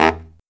LOHITSAX16-L.wav